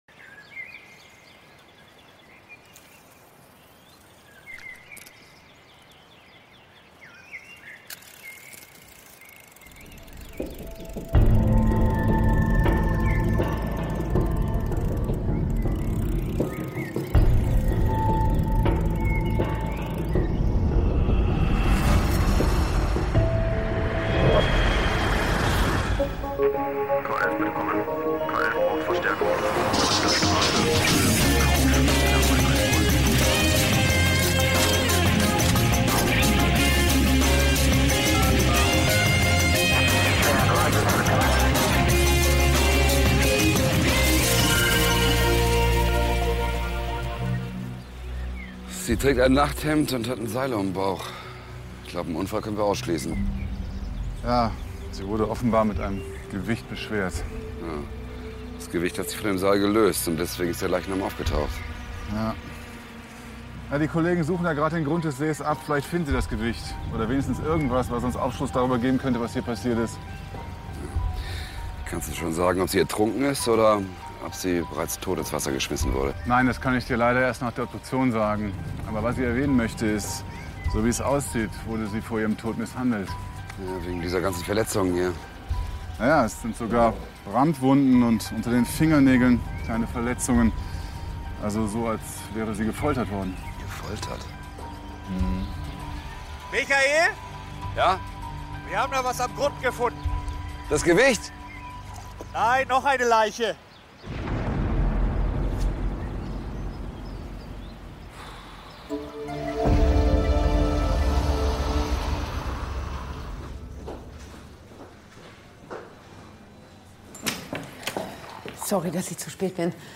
Grausamer Fund: Drei Menschen ertränkt - K11 Hörspiel